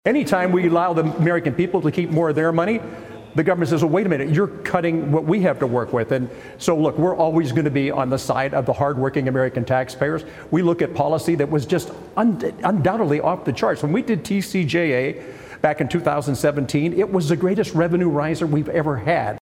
PA Congressman Mike Kelly Speaks Out on Pending Expiration of Tax Cuts